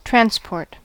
Ääntäminen
IPA : /ˈtɹænz.pɔːt/ IPA : /tɹɑːnˈspɔːt/ IPA : /ˈtɹænz.pɔɹt/ IPA : /tɹænsˈpɔɹt/ IPA : /tɹænsˈpɔːt/